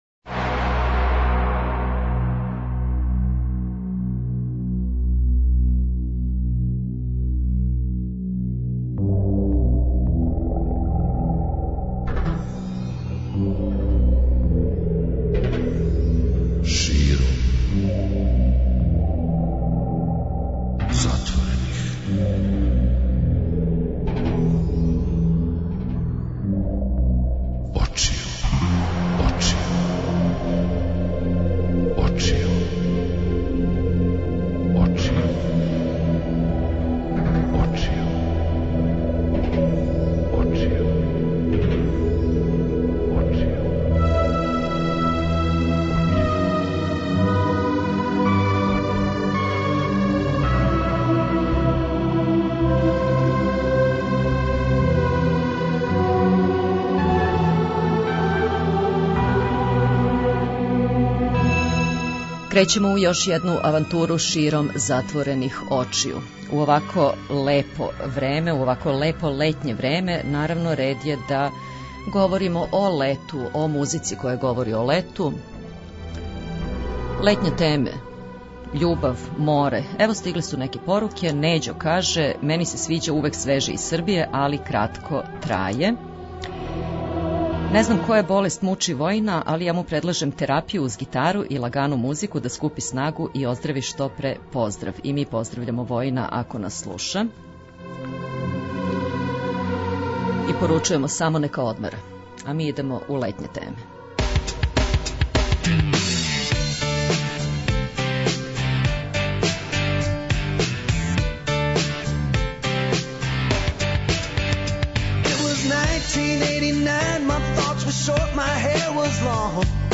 преузми : 57.20 MB Широм затворених очију Autor: Београд 202 Ноћни програм Београда 202 [ детаљније ] Све епизоде серијала Београд 202 We care about disco!!!